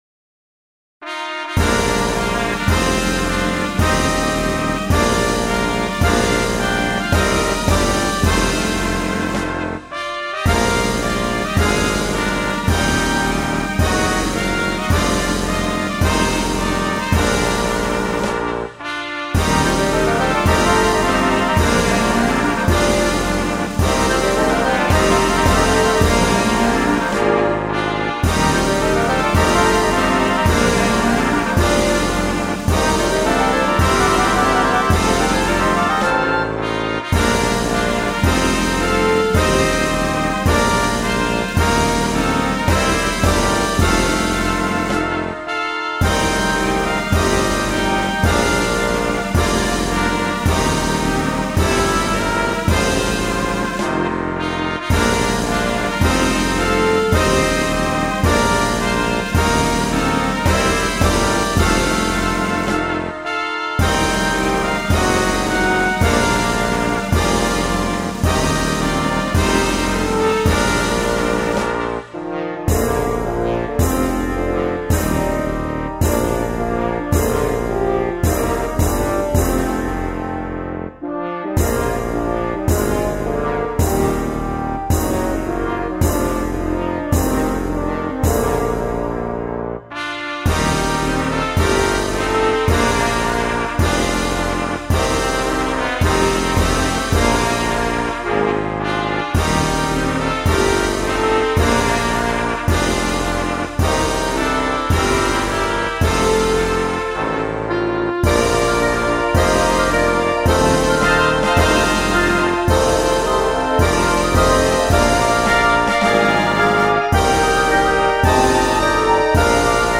BGM
ロング明るい激しい